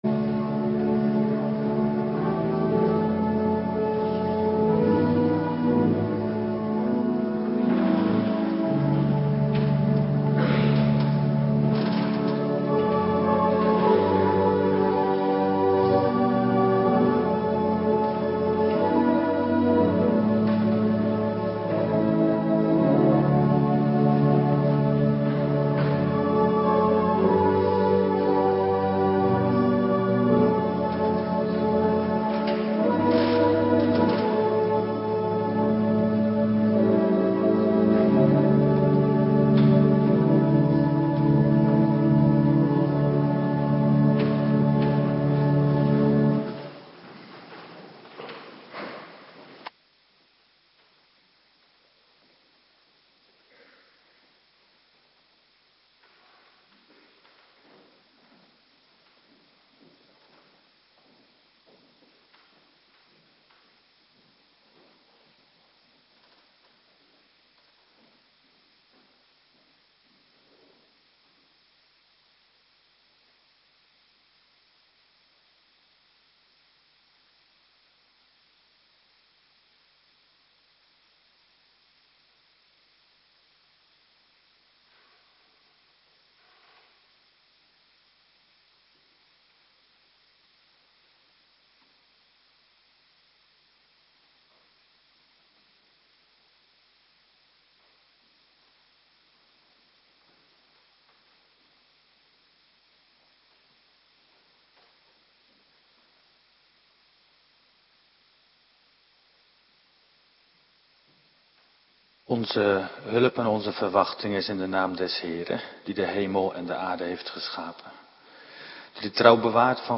Woensdagavond Bijbellezing